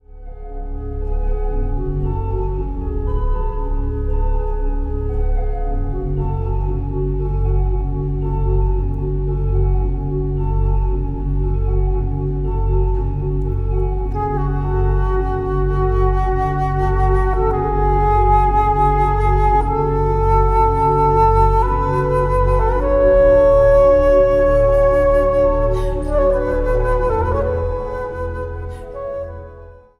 fluit, altfluit en piccolo
orgel
trompet
slagwerk.
Instrumentaal | Dwarsfluit
Instrumentaal | Piccolo
Instrumentaal | Trompet